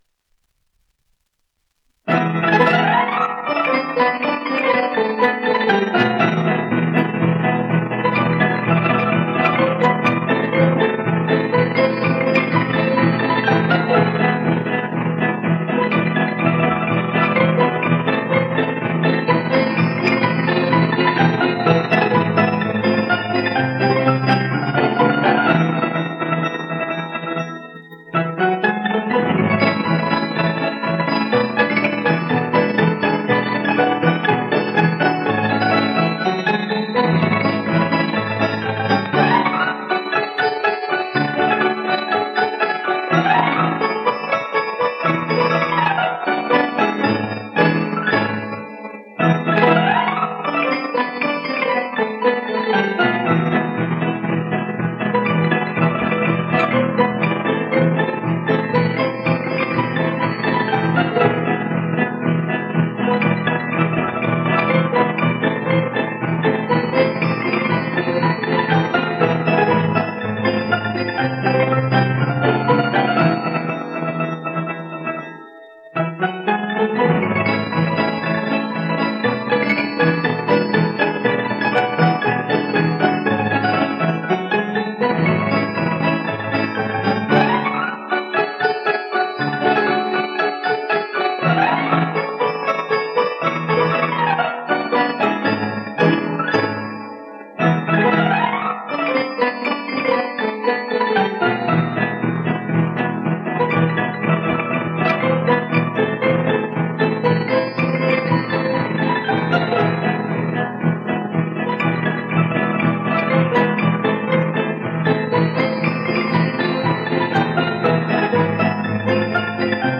Agua, Azucarillos y Aguardiente: Pasodoble (sonido remasterizado)
1 disco : 78 rpm ; 25 cm.
organillo